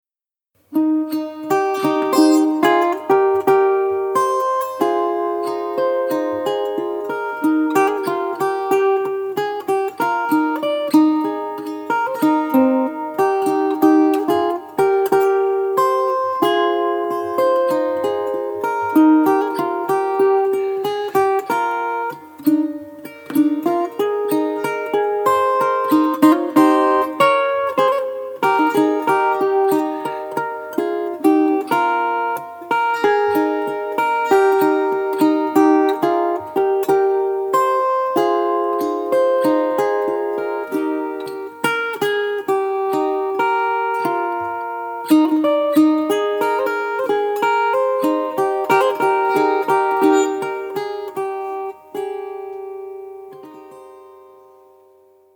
Tears of Morning – High Strung version